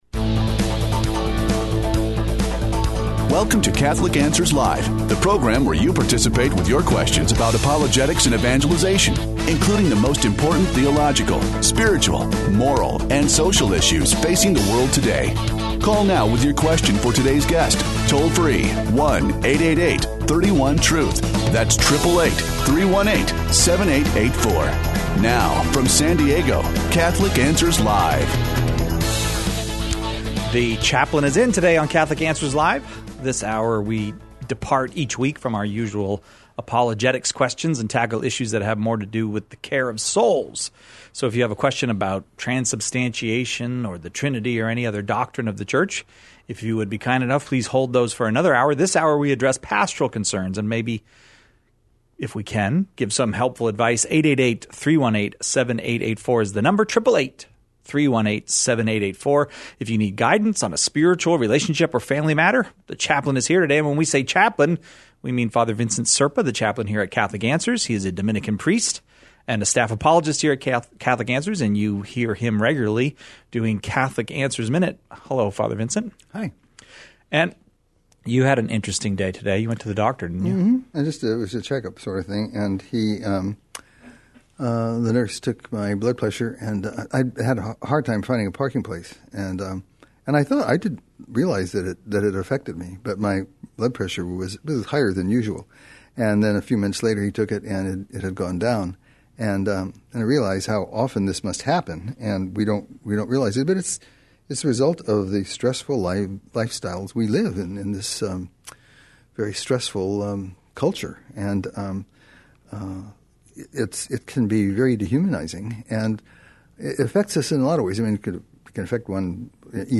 Special emphasis is given to the power of meditation on Christ’s Passion. Questions Covered: How do we make Christianity more accessible to people when some aspects are hard to understand?